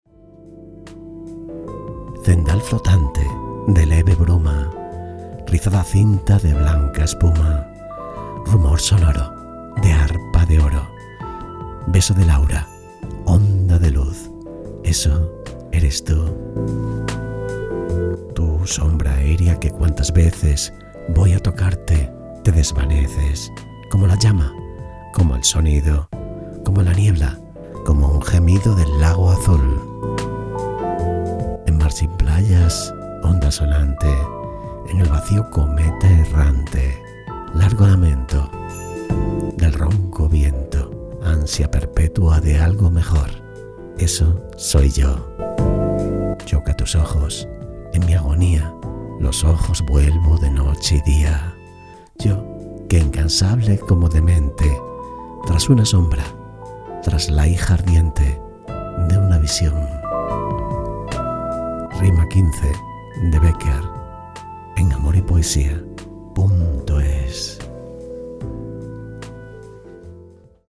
Poesias de amor recitadas de Becquer